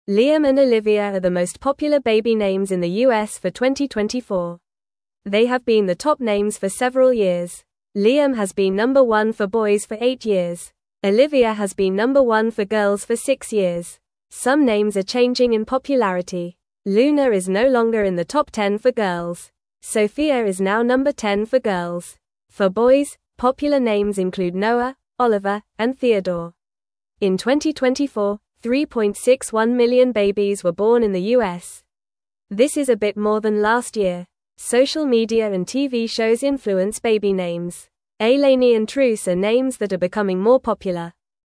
Fast
English-Newsroom-Lower-Intermediate-FAST-Reading-Liam-and-Olivia-Are-Top-Baby-Names-for-2024.mp3